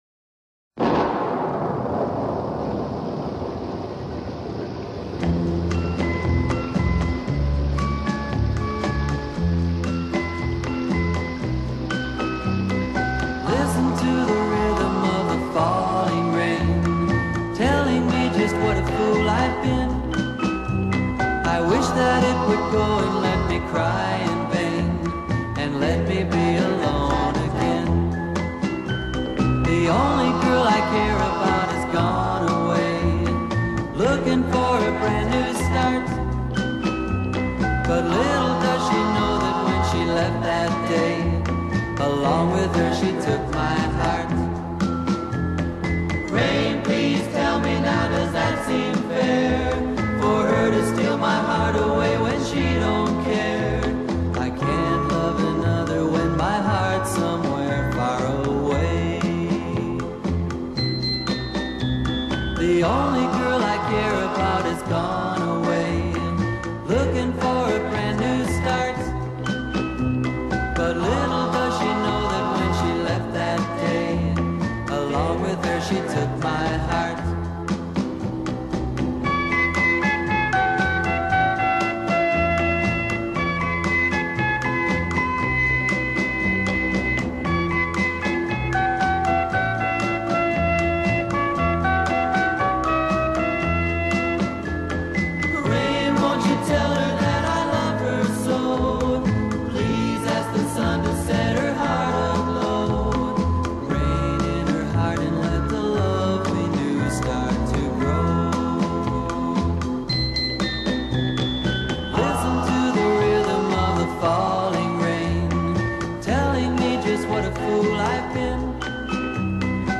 Easy Listening, Oldies | Publisher: n/a